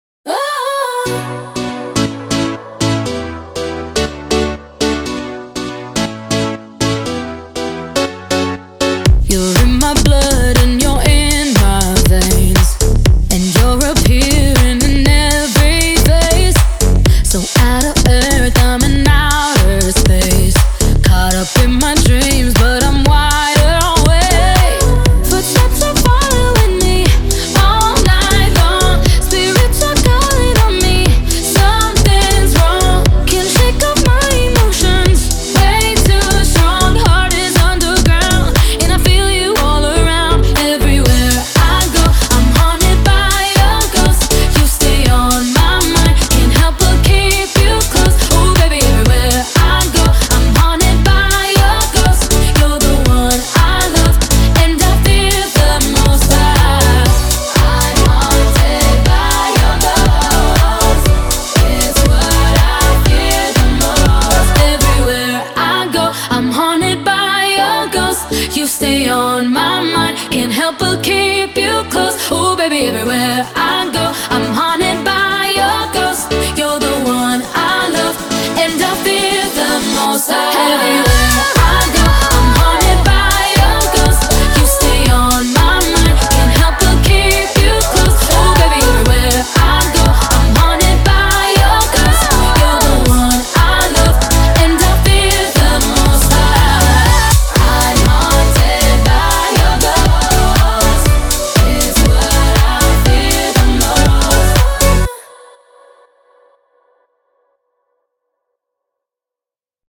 BPM120
Audio QualityCut From Video